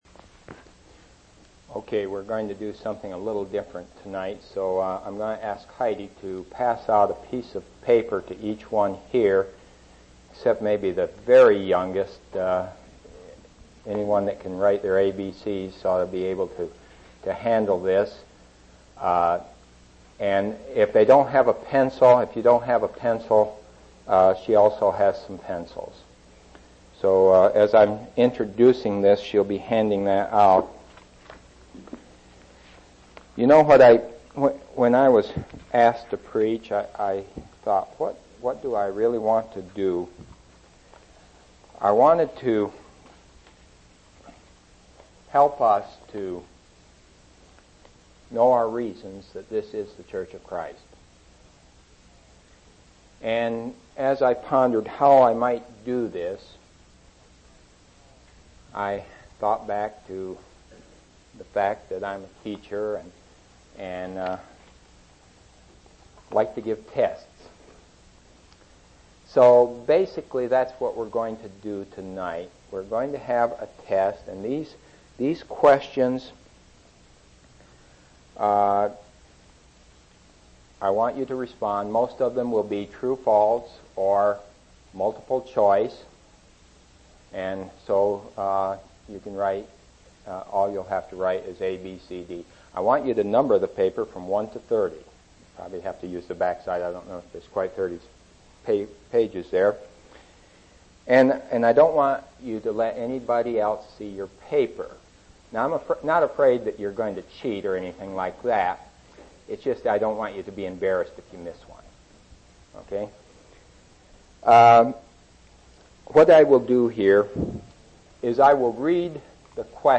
4/24/1988 Location: East Independence Local Event